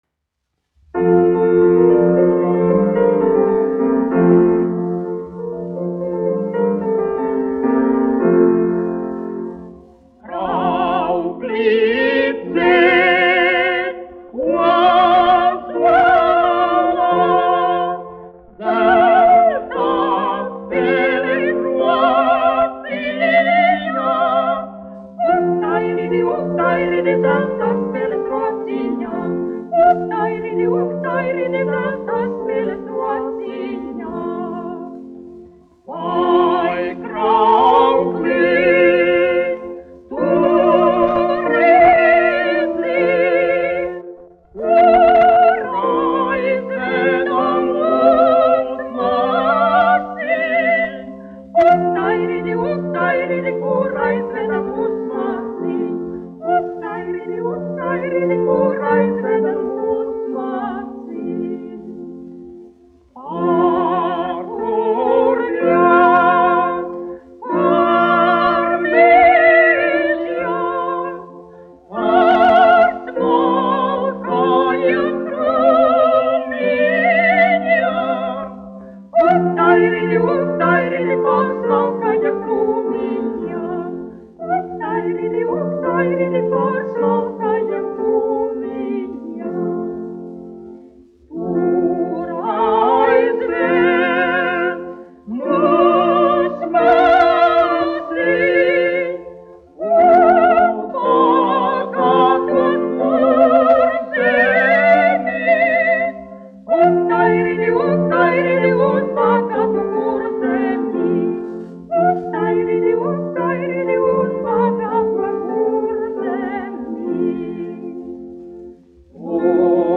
1 skpl. : analogs, 78 apgr/min, mono ; 25 cm
Latviešu tautasdziesmas
Vokālie dueti ar klavierēm
Skaņuplate